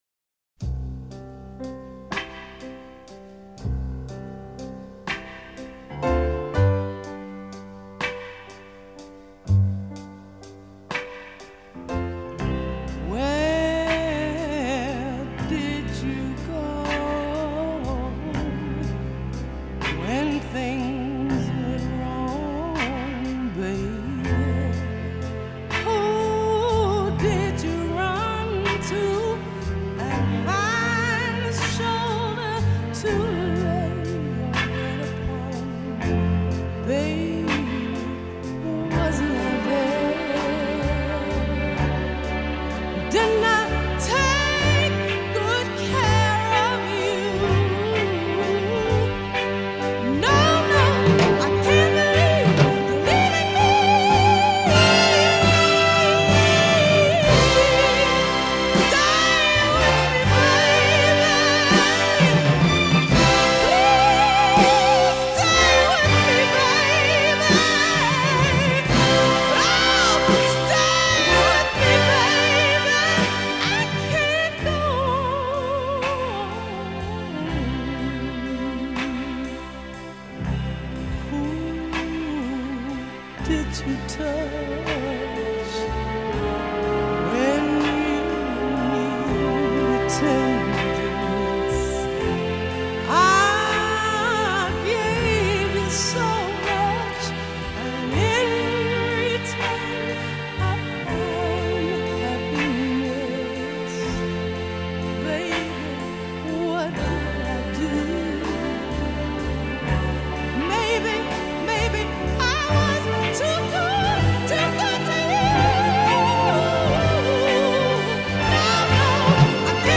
one of the great soul hits of the 60’s